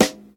• Prominent Rock Snare Sample G# Key 92.wav
Royality free steel snare drum sample tuned to the G# note. Loudest frequency: 2160Hz
prominent-rock-snare-sample-g-sharp-key-92-Vrj.wav